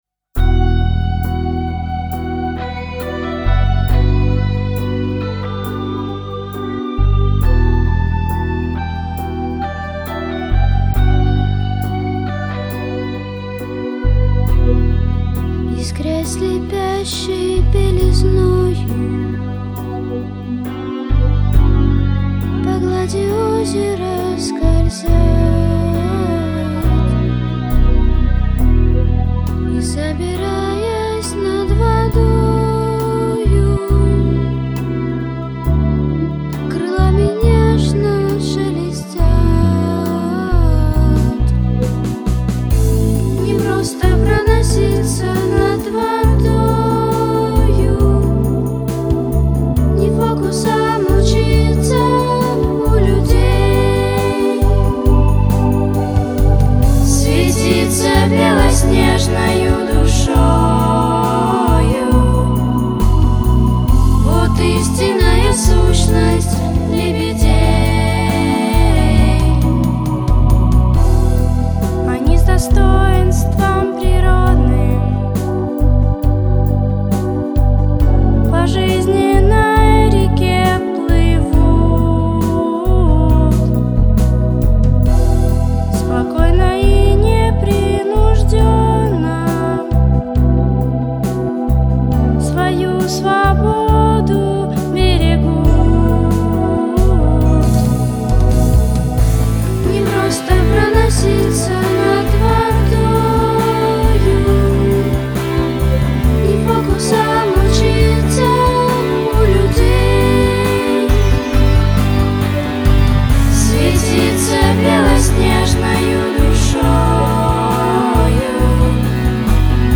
Песни исполняют: Участники спектаклей
Записано в студии Easy Rider в сентябре-ноябре 2005 года